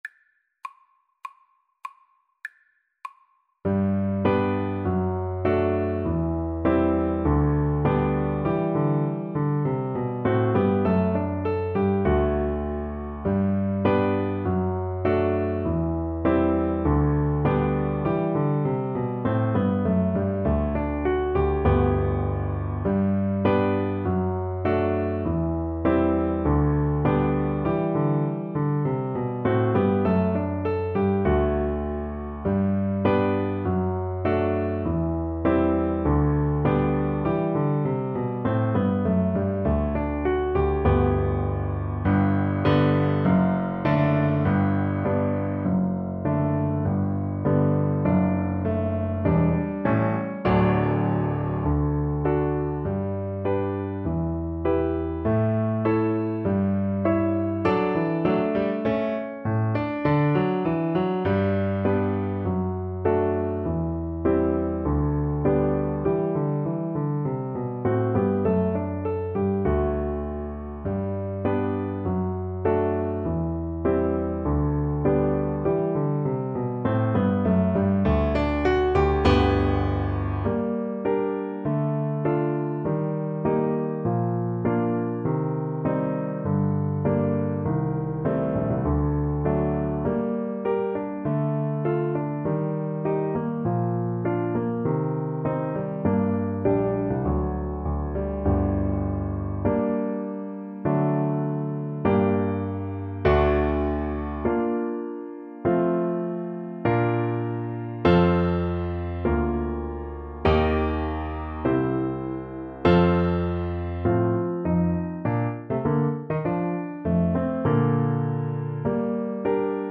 ~ = 100 Allegretto
G major (Sounding Pitch) (View more G major Music for Flute )
2/2 (View more 2/2 Music)
Classical (View more Classical Flute Music)